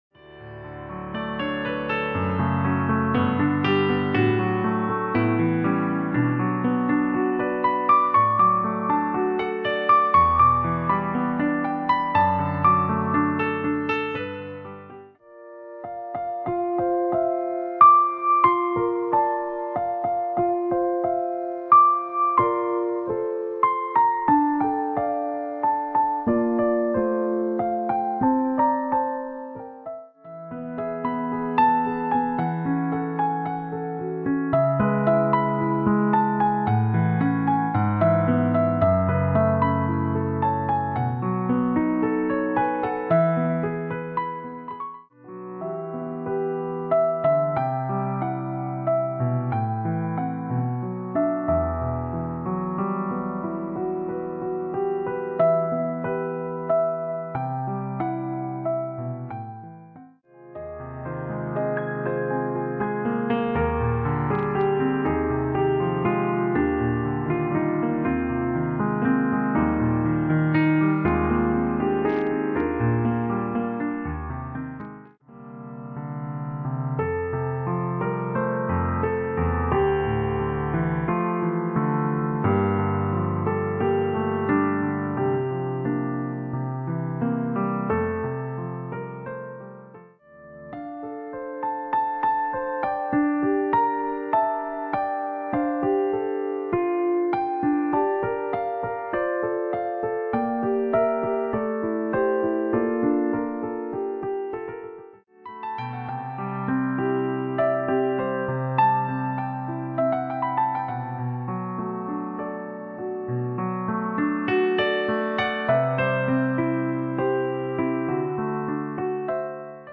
Solo Instrument